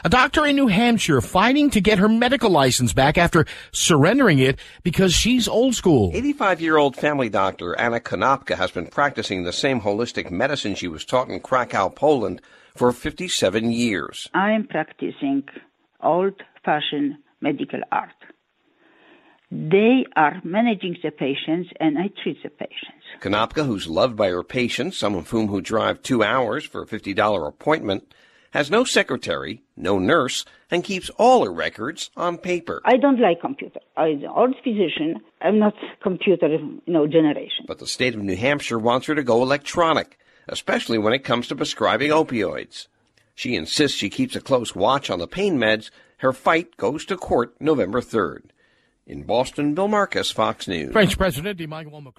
(BOSTON) OCT 14 – A BELOVED DOCTOR IN NEW HAMPSHIRE IS FIGHTING TO WIN BACK HER MEDICAL LICENSE AFTER IT WAS SURRENDERED BECAUSE SHE REFUSES TO MODERNIZE.